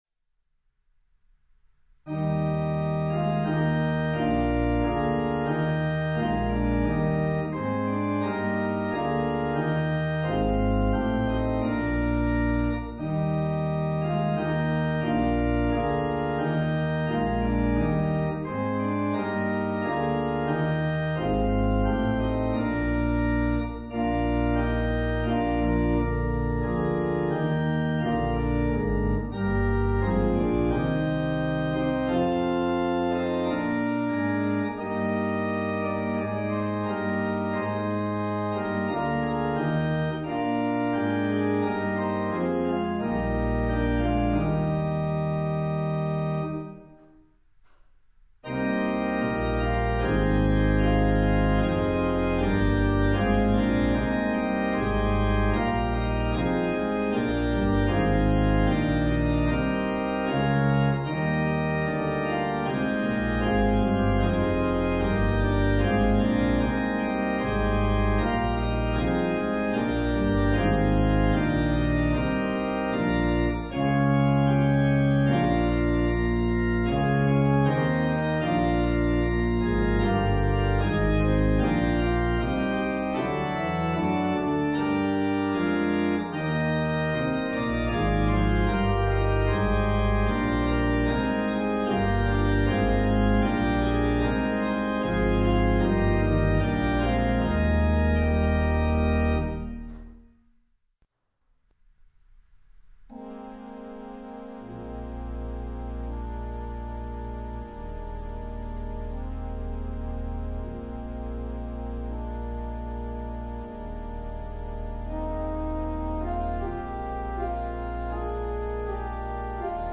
four variations